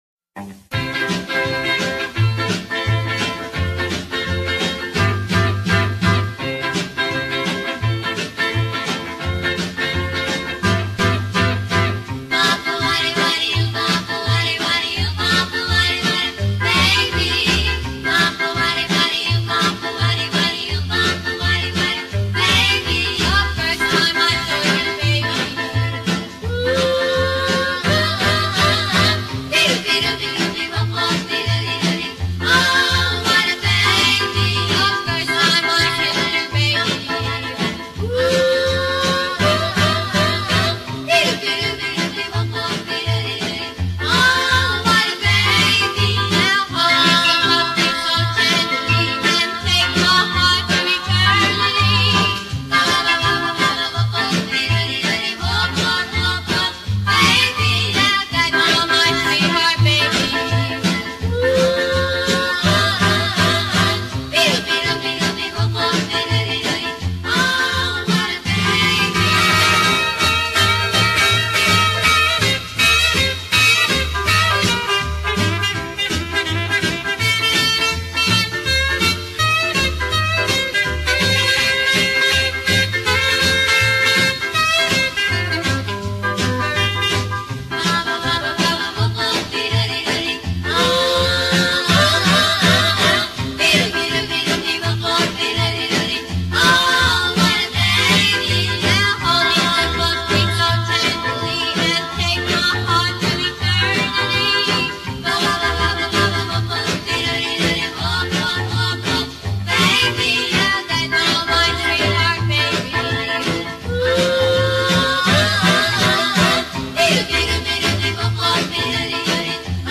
singing in mono